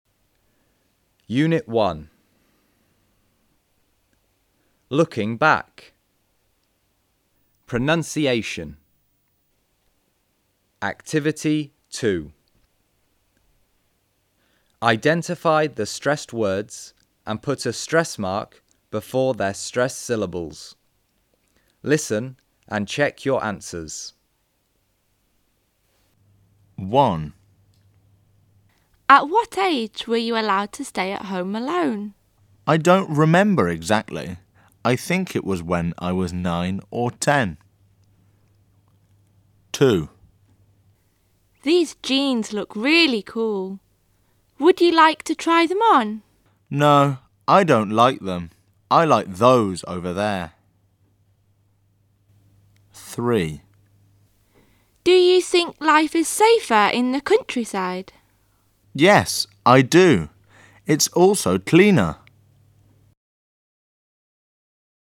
Sách nói | Tiếng Anh 11_Tập 1 (Track 01 -> Track 10)